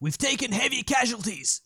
Voices / Male